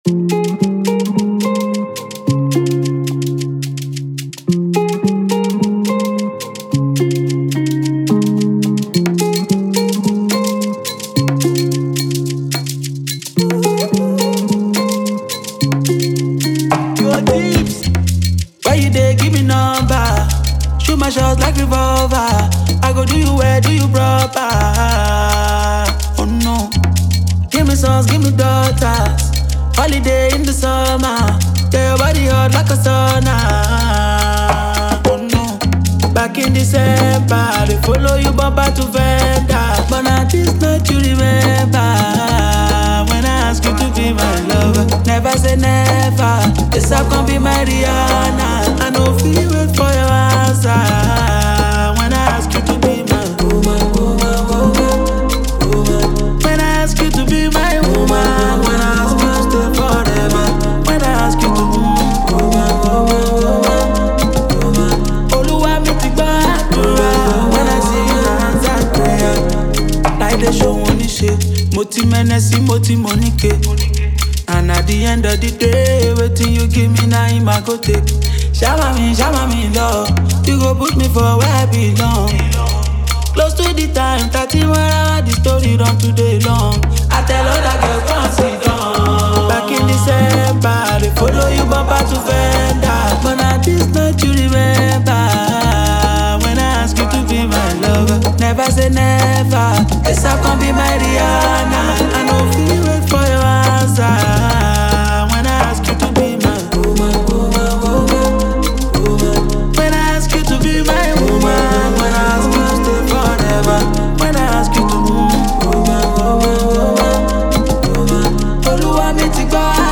Afrobeats
Known for his hit-making ability and smooth vocals